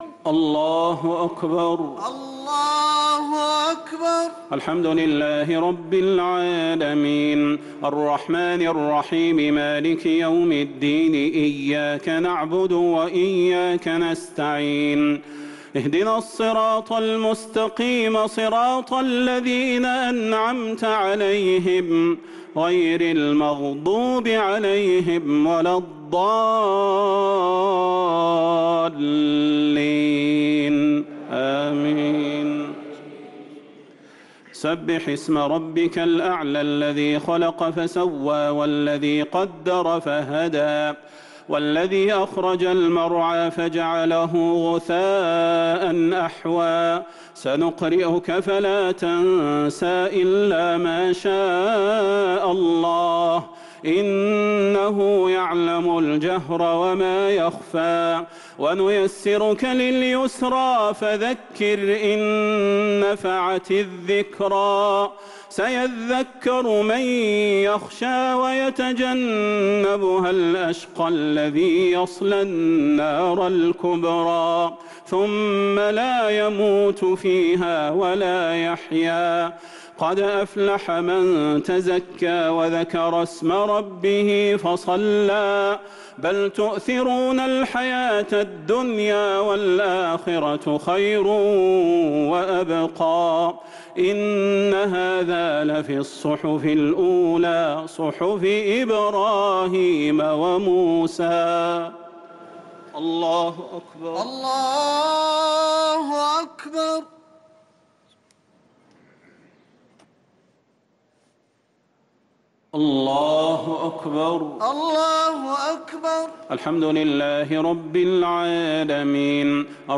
صلاة التراويح ليلة 14 رمضان 1444 للقارئ صلاح البدير - الشفع والوتر - صلاة التراويح